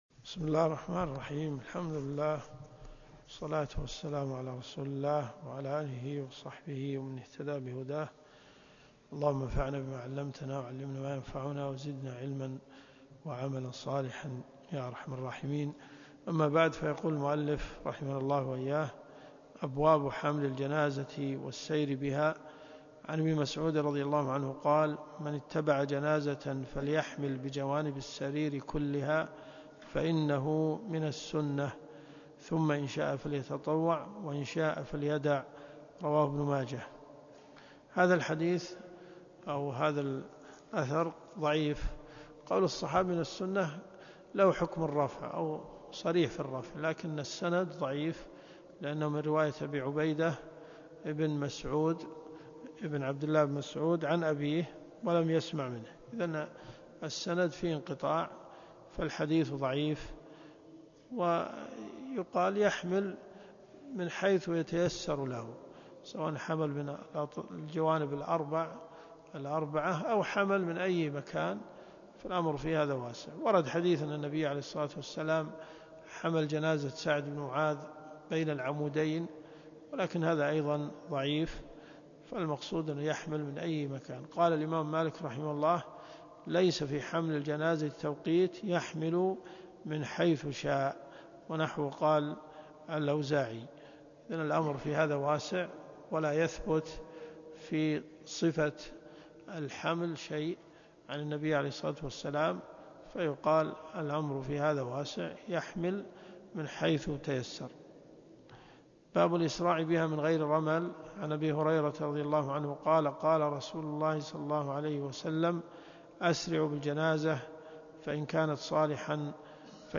الدروس الشرعية
الرياض . حي أم الحمام . جامع الملك خالد